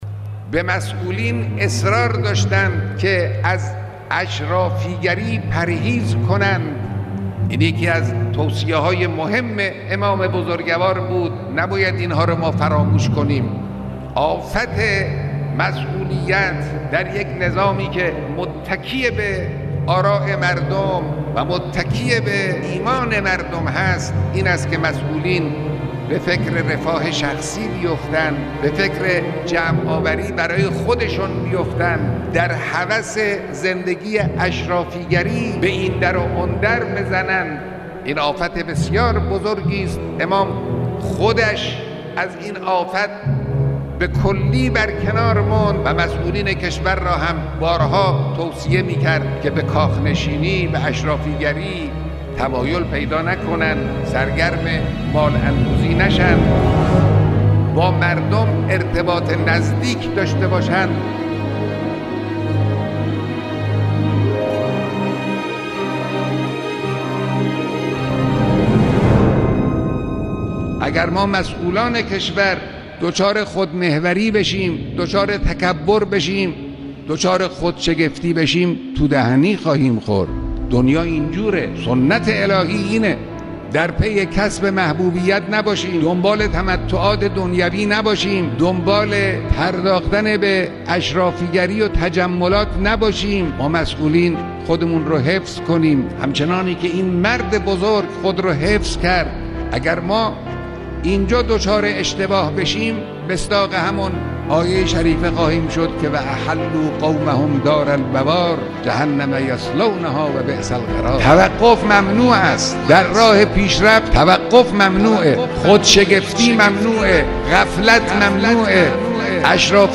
خبرگزاری تسنیم: امام خامنه ای طی بیاناتی در توصیف سیره امام خمینی (ره)، اشرافی گری را آفت نظام اسلامی دانستند.